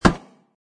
metalgrass.mp3